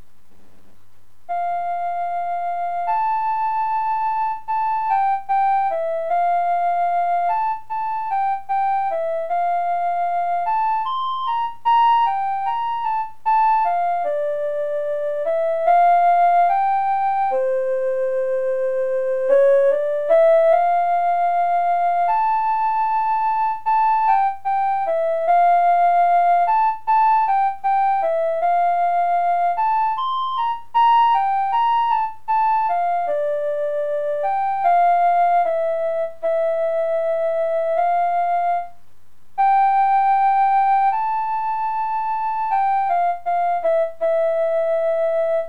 - Live MIDI keyboard performance (File size 3.8 MB)